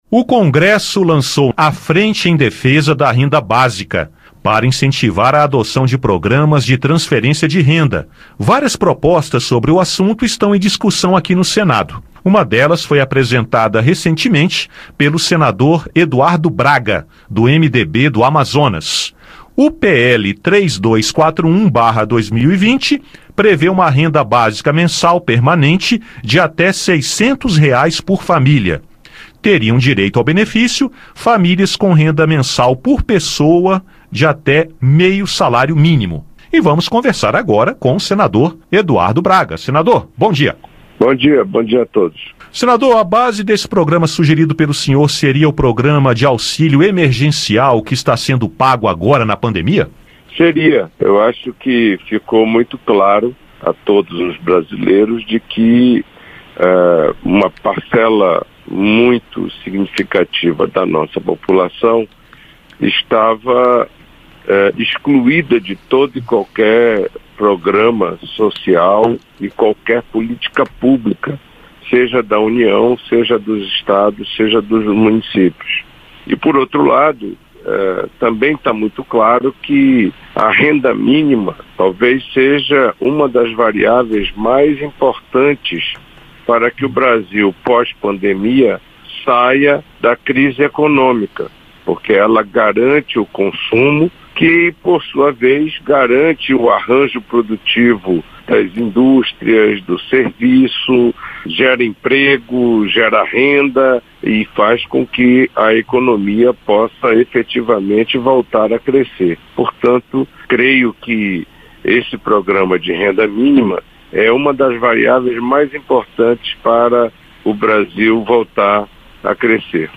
Entrevista originalmente veiculada em 24/07/2020.